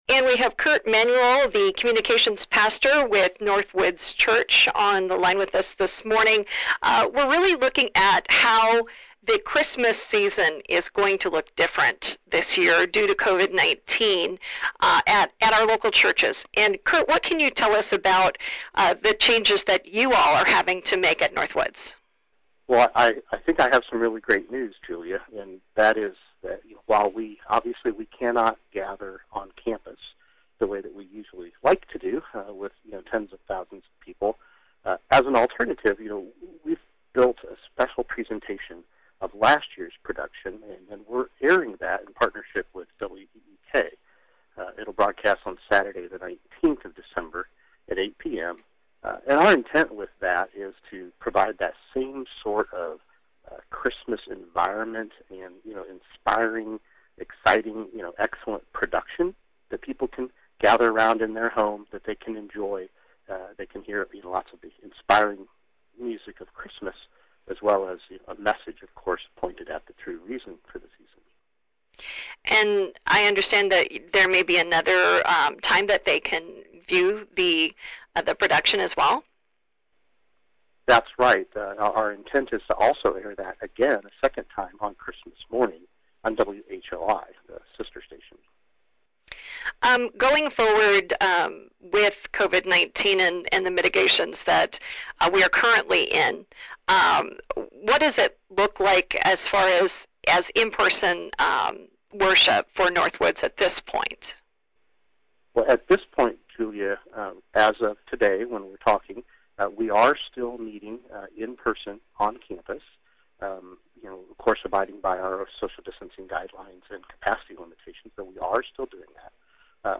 Here’s the full interview